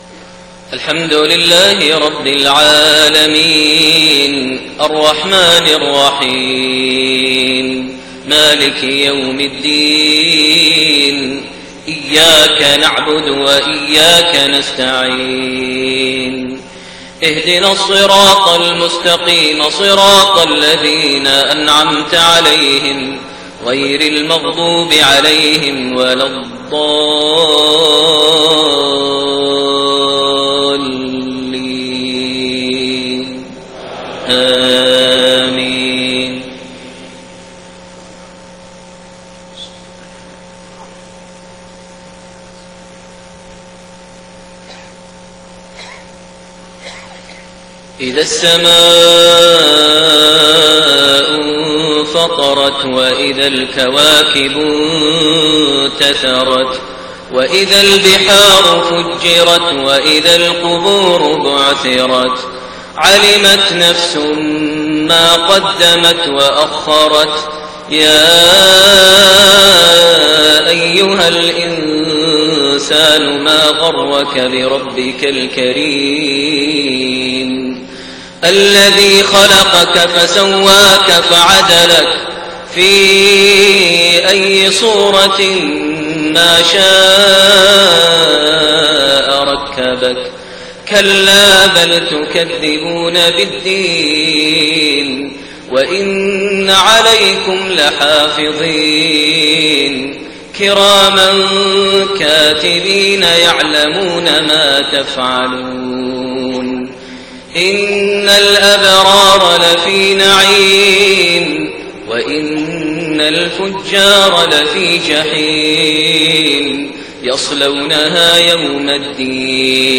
صلاة المغرب3-4-1430 سورتي الإنفطار والزلزلة > 1430 هـ > الفروض - تلاوات ماهر المعيقلي